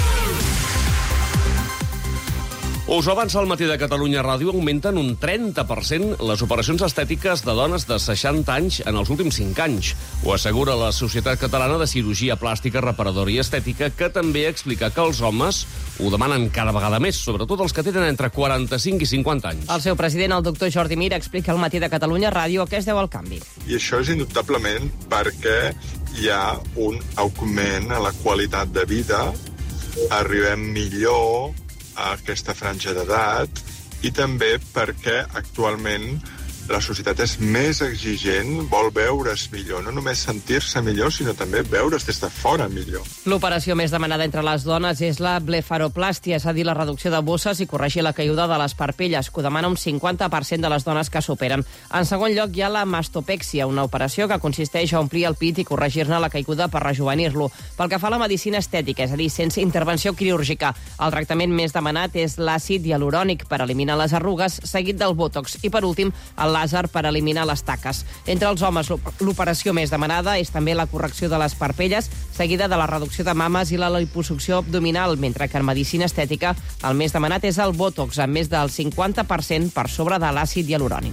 AQUÍ la notícia que es va emetre a El Matí de Catalunya Ràdio en tres franges horàries.